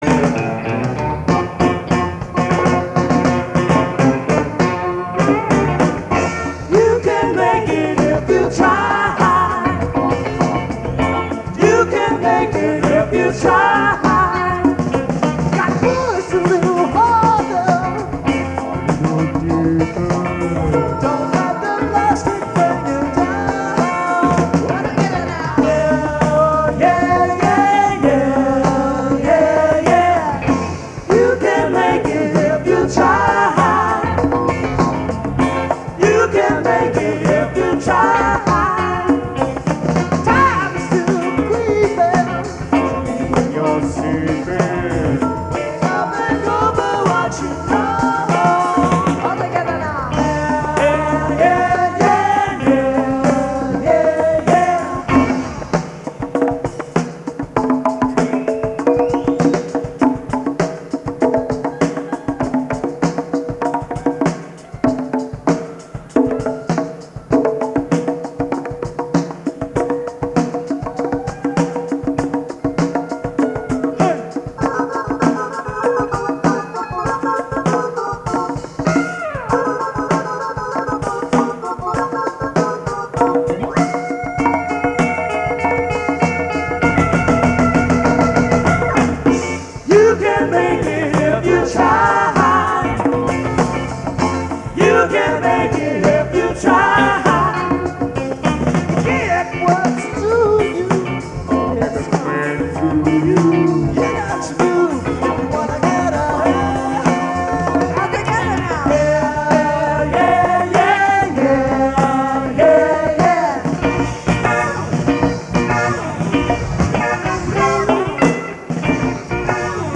Live Recordings
Norwalk, CT